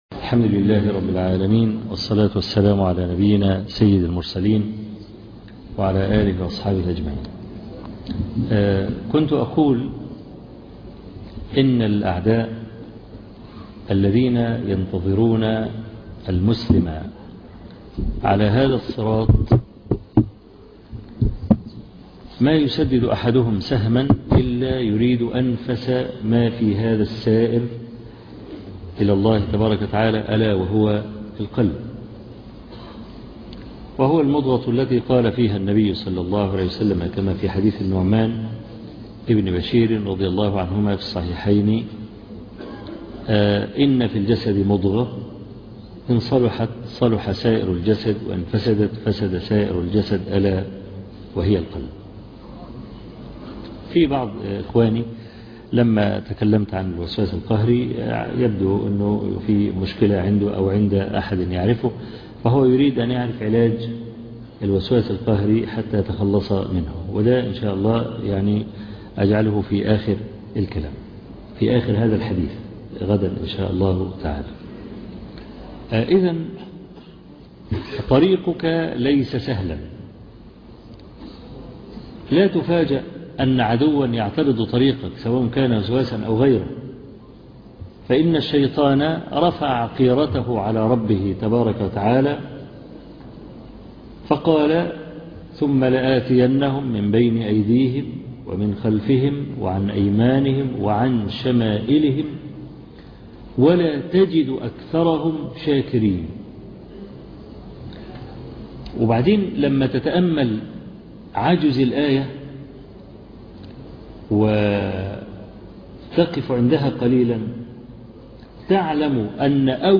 شرح كتاب تلخيص روضة الناظر وجنّة المناظر الدرس السادس والعشرون